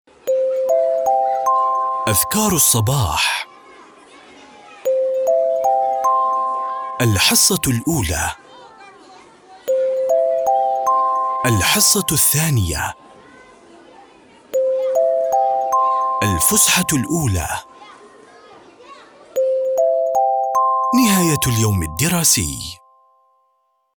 Arabische voice-over
Commercieel, Diep, Natuurlijk, Vertrouwd, Zakelijk
Audiogids